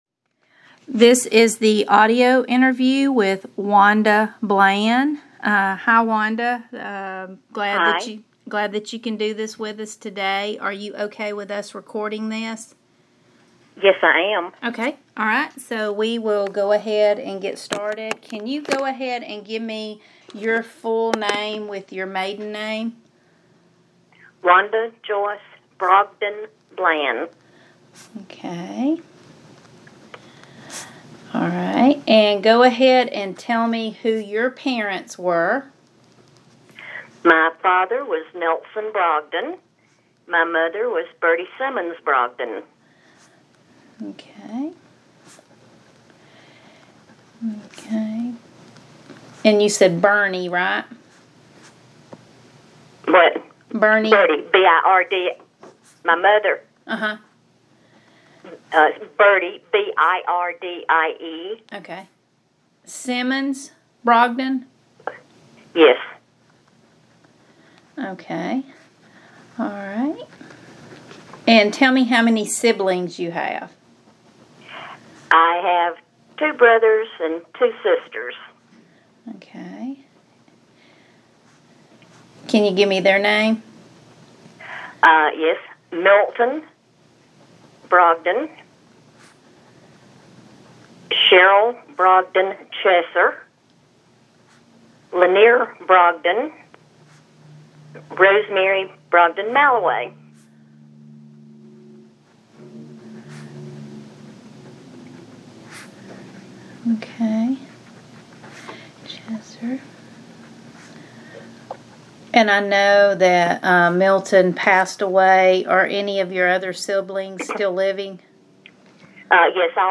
Oral histories
via telephone